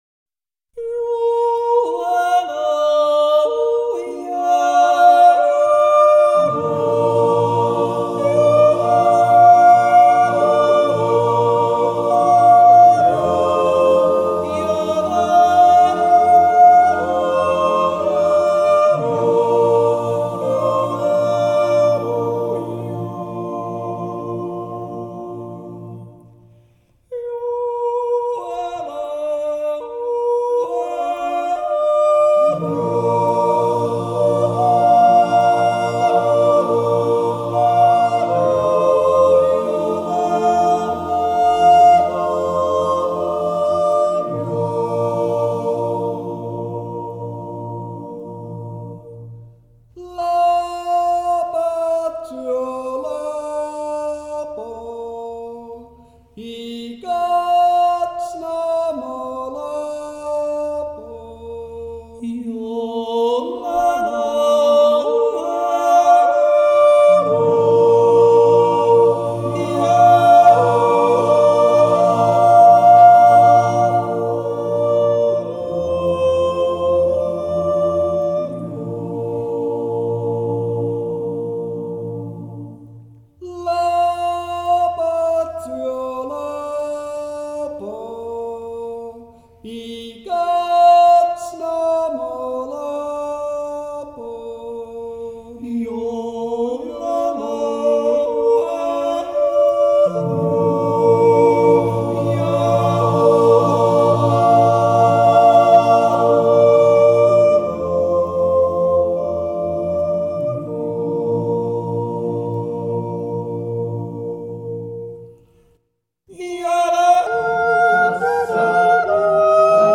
Bätruef-Juiz. Natural yodel.
Jodlerklub Tälläbuebe